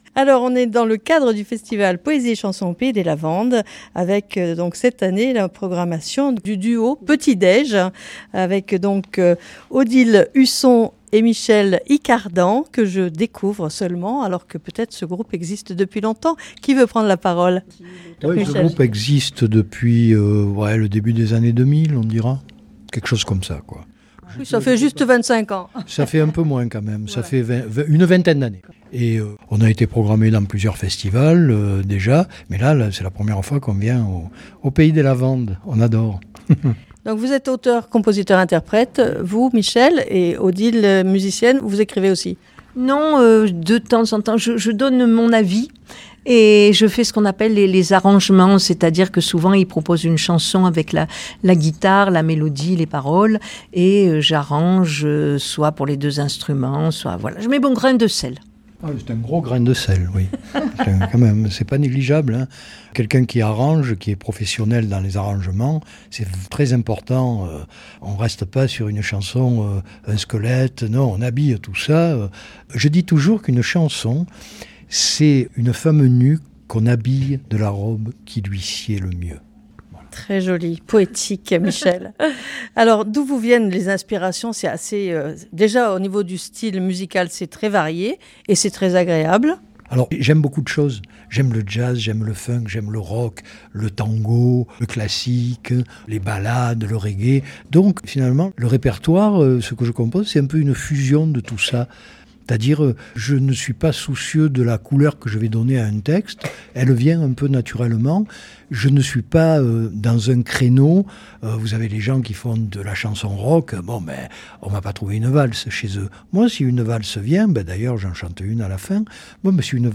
Interview du duo Petidej